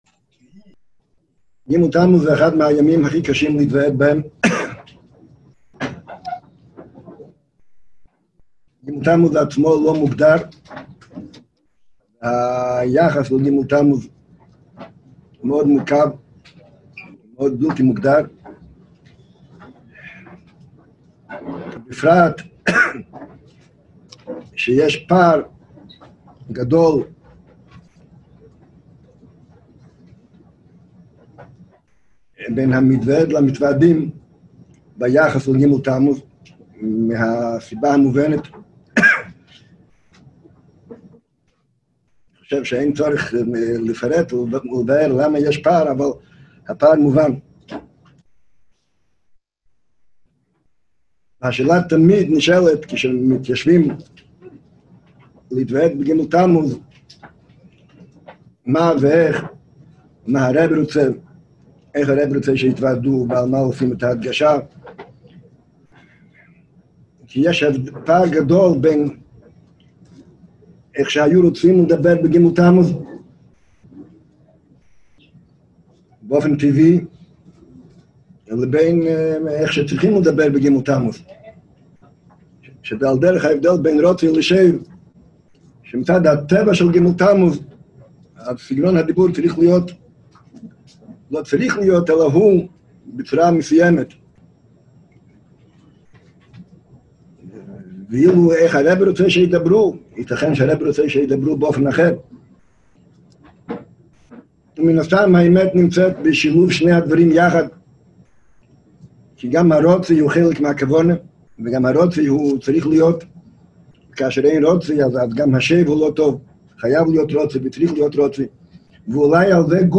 התוועדות ג תמוז